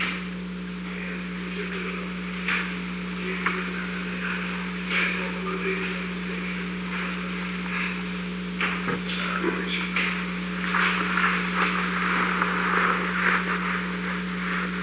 Secret White House Tapes
Location: Oval Office
The President met with an unknown person